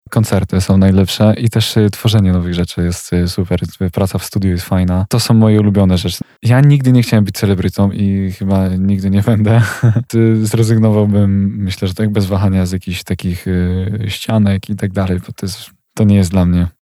[PRRC] Renesans rocka w wydaniu Gen Z – rozmowa